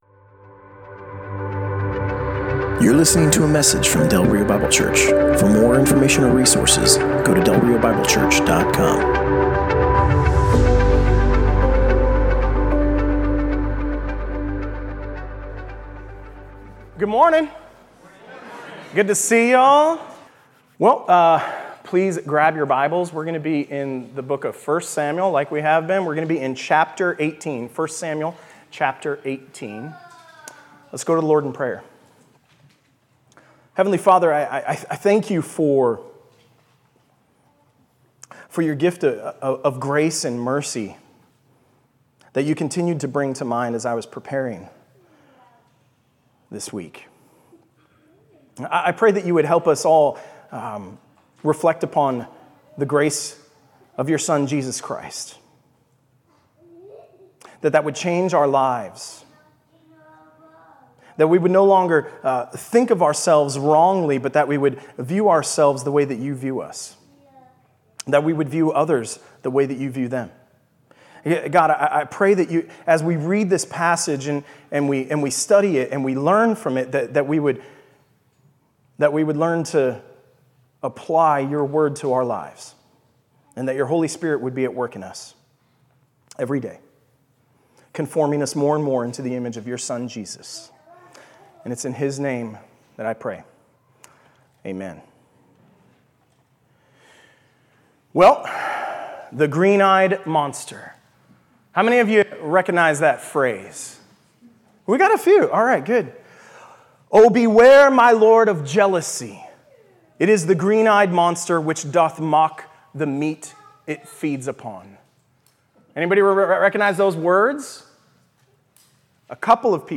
Passage: 1 Samuel 18: 1-30 Service Type: Sunday Morning